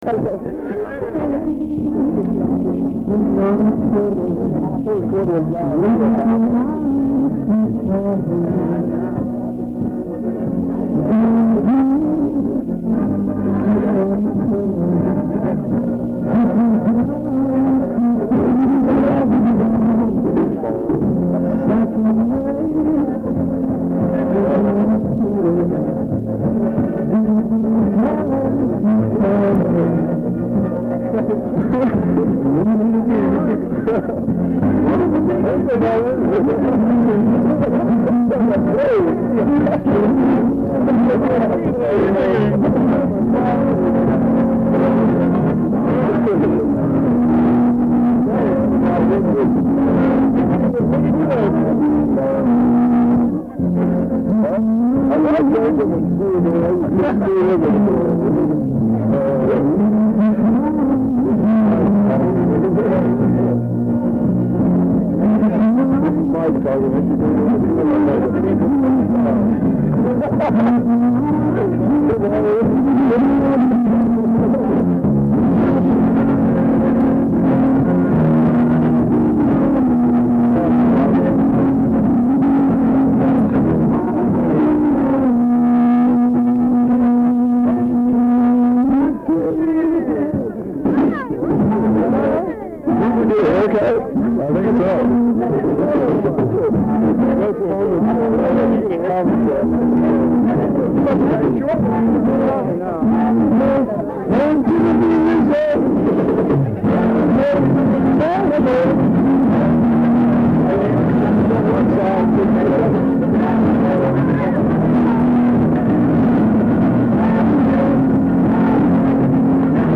This was an outdoor concert held on a Sunday afternoon in the summer of 1972 in Harrison Smith Park in Upper Sandusky, Ohio.
Disclaimer: The audio in the clips is of extremely poor quality! Plus my friends and I were talking throughout.